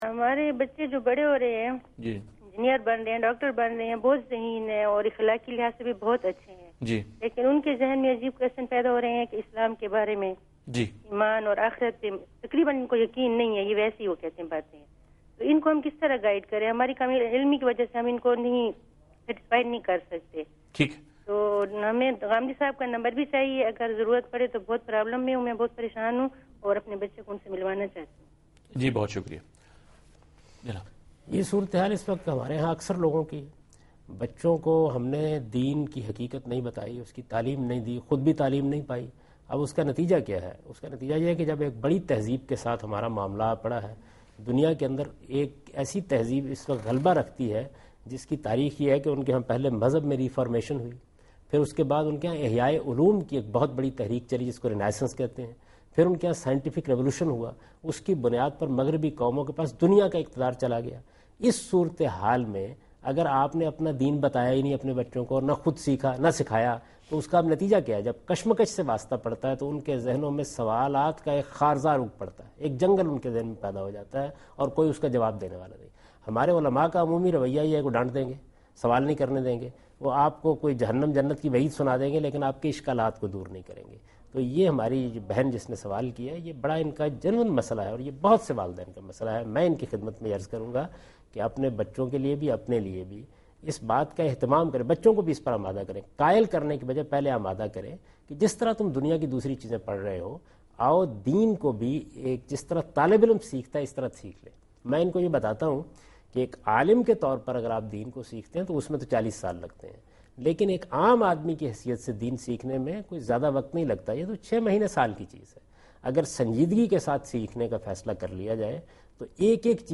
TV Programs
Answer to a Question by Javed Ahmad Ghamidi during a talk show "Deen o Danish" on Duny News TV